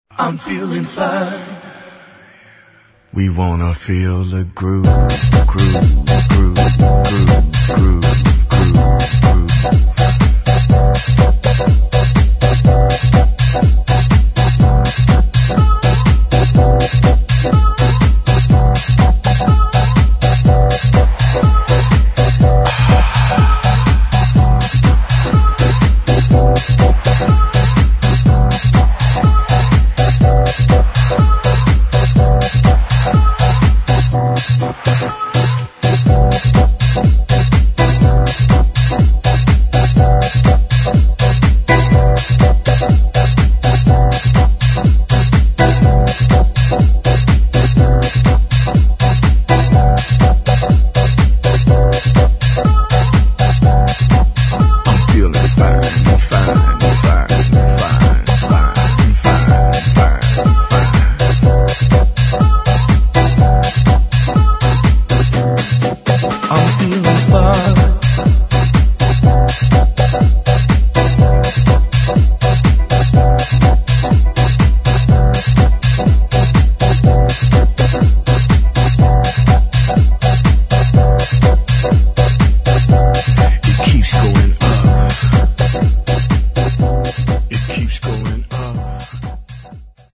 [ DEEP HOUSE / TECH HOUSE ]